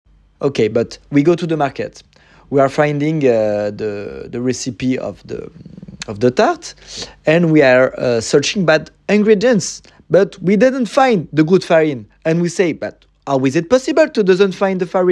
Accent fort français